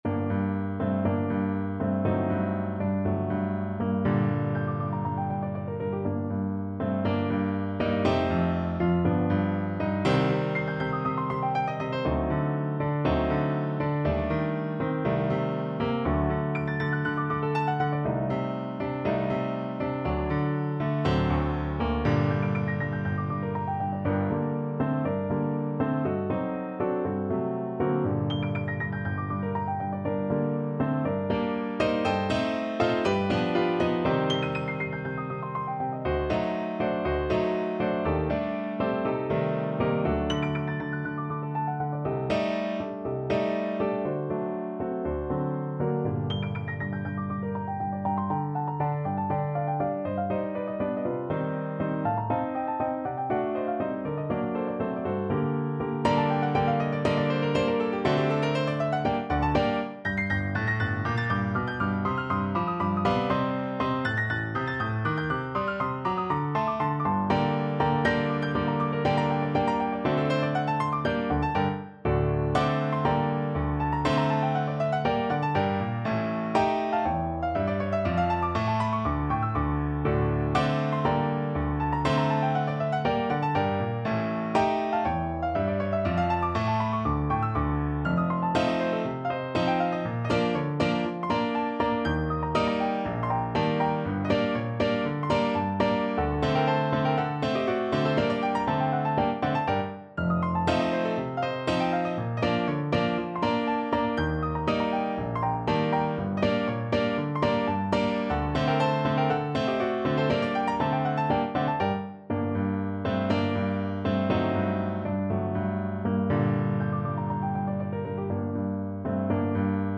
No parts available for this pieces as it is for solo piano.
2/4 (View more 2/4 Music)
Allegro molto (View more music marked Allegro)
Classical (View more Classical Piano Music)